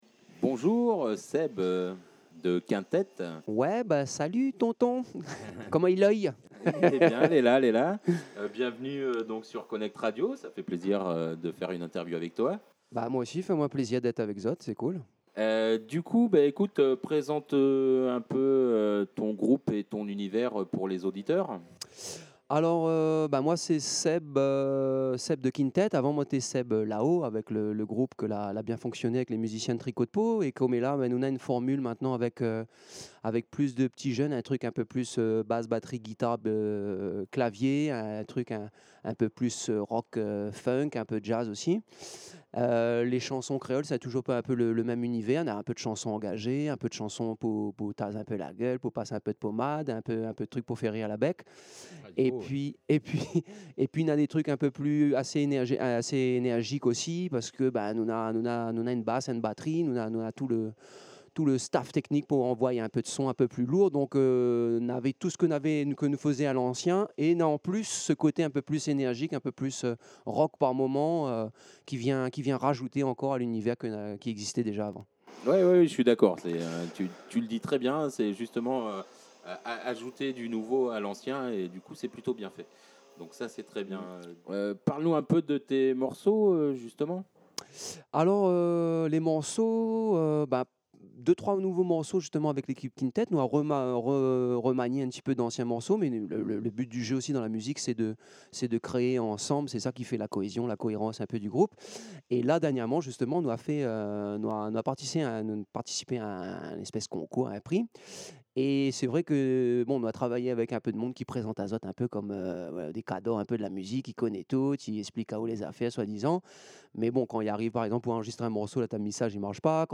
INTERVIEW: KINTET
interview-kintet-2.mp3